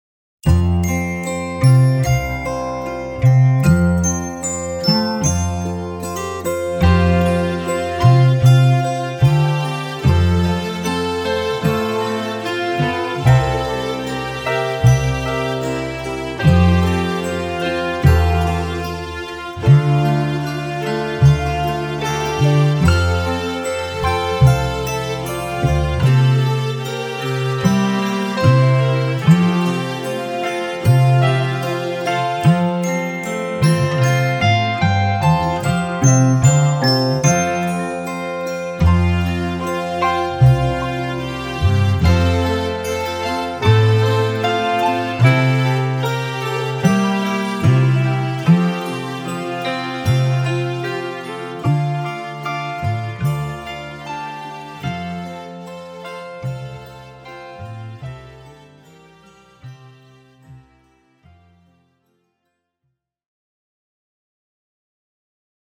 Latviešu tautas dziesma Play-along.
Spied šeit, lai paklausītos Demo ar melodiju